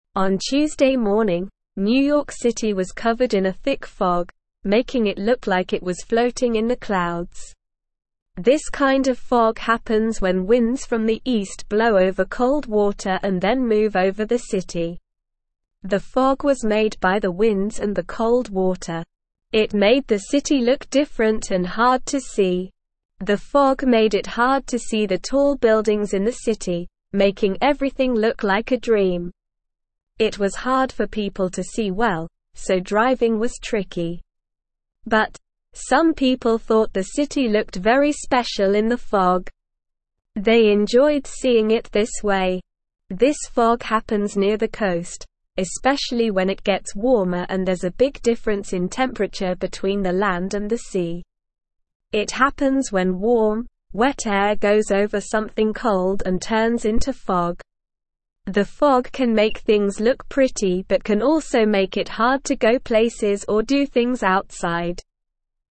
Slow
English-Newsroom-Lower-Intermediate-SLOW-Reading-New-York-City-Covered-in-Thick-Fog.mp3